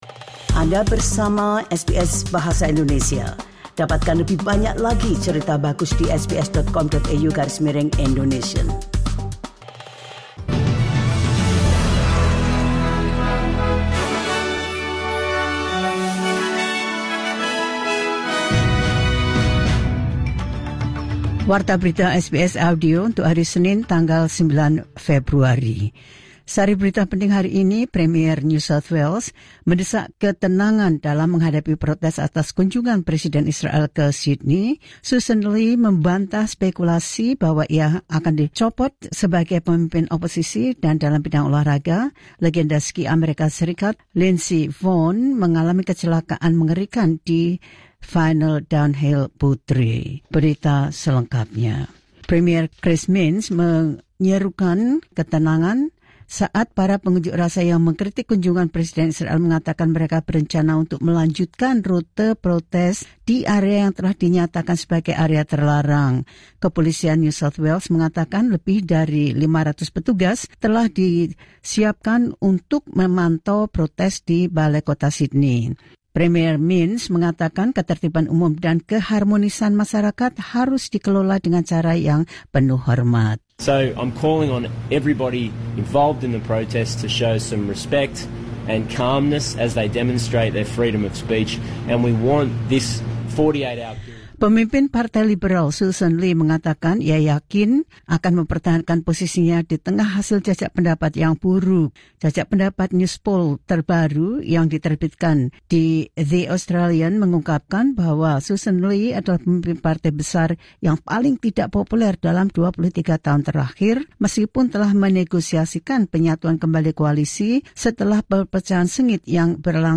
The latest news SBS Audio Indonesian Program – Mon 09 February 2026.